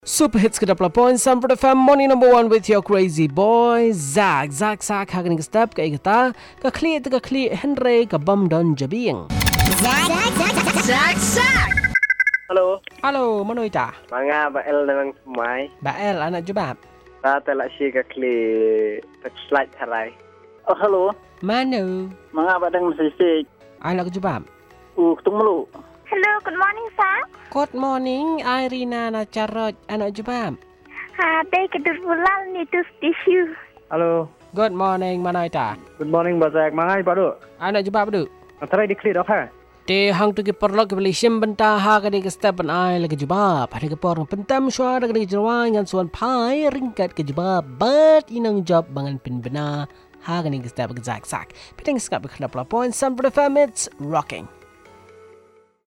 Callers